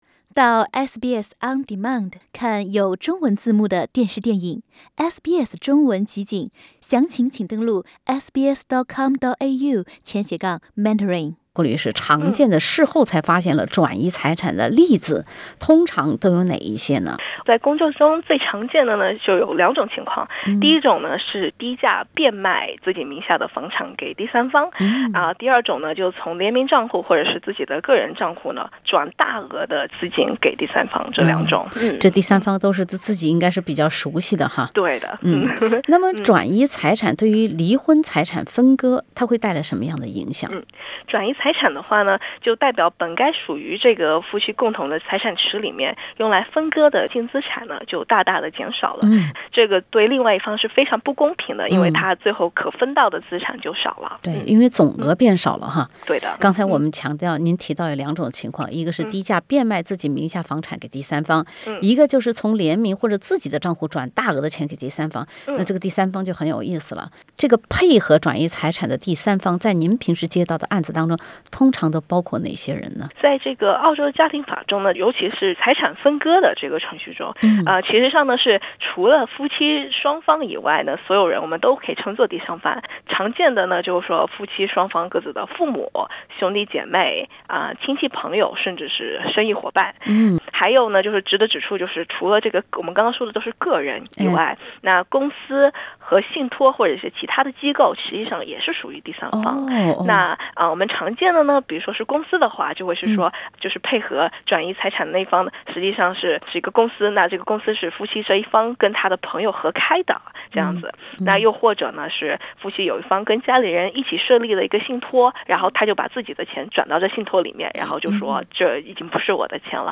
在不知情的前提下，被“前任”转移的财产也有办法追回。（点击上图收听采访录音）